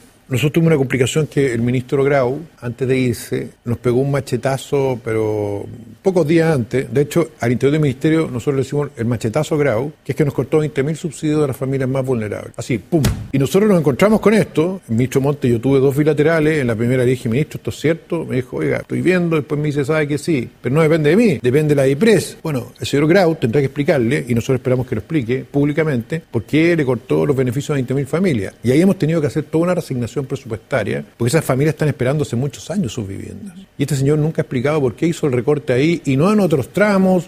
En entrevista con Estado Nacional, el secretario de Estado señaló que, antes de dejar el Gobierno, el exministro de Economía, Nicolás Grau, impulsó una reducción de recursos que dentro del ministerio han denominado “el machetazo Grau”.